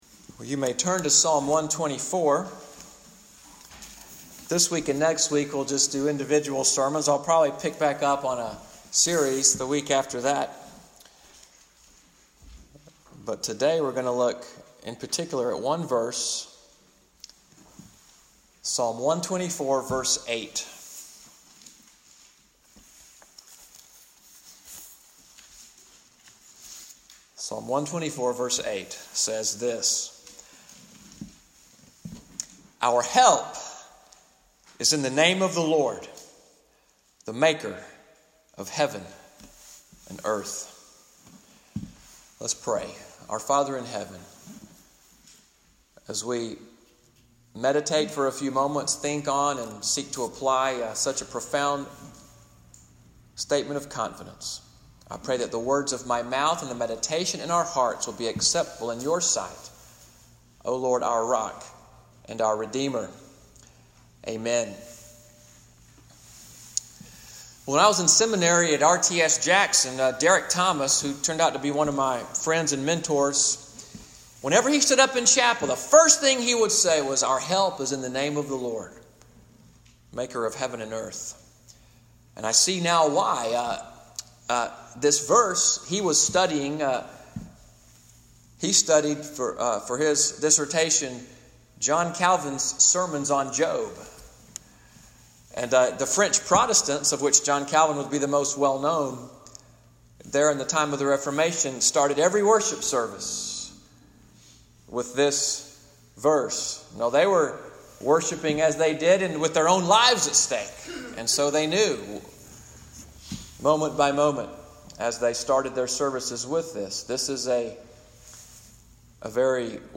Sermon audio from Morning Worship at Little Sandy Ridge Presbyterian Church in Fort Deposit, AL from the sermon, “Confidence in the Lord,” May 27, 2018.